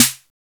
808 LOOSESN.wav